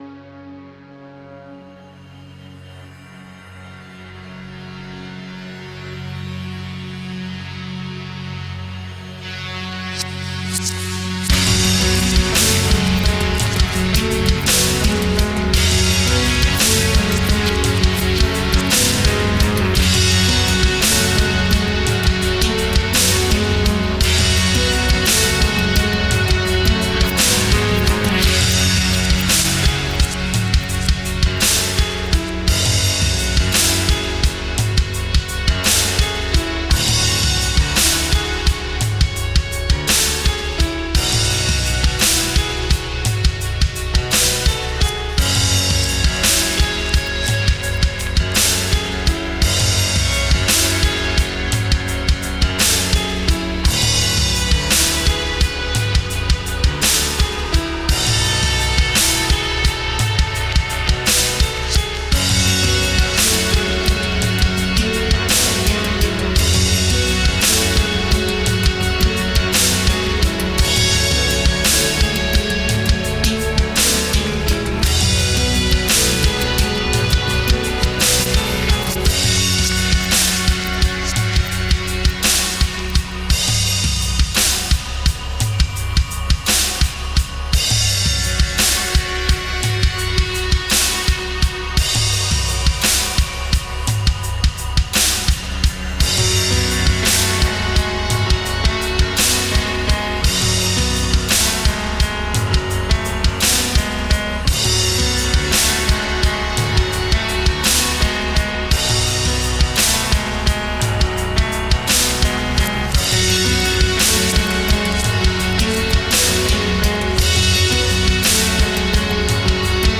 Mesmerizing cinematic compositions with a touch of rock.